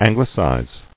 [An·gli·cize]